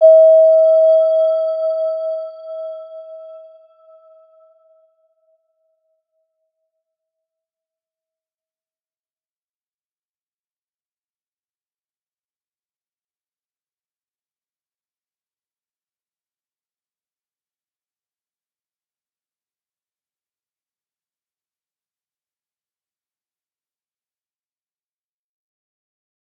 Round-Bell-E5-f.wav